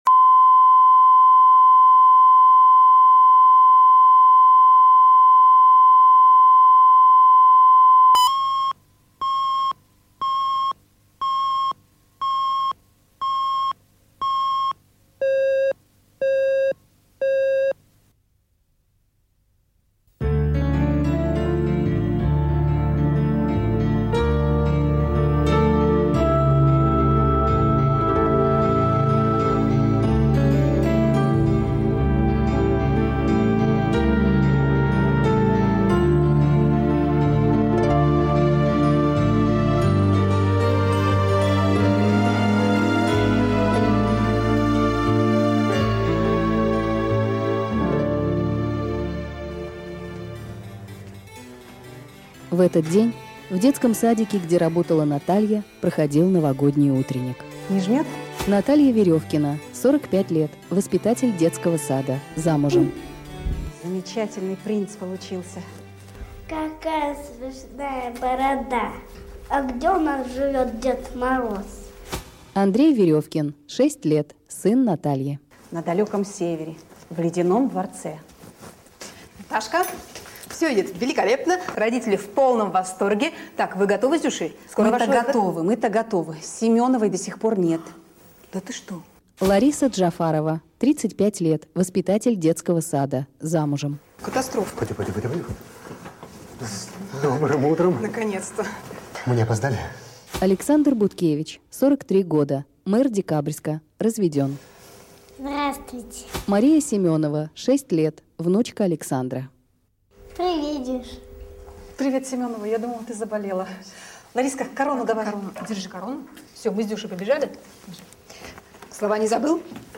Аудиокнига Гордый дед мороз | Библиотека аудиокниг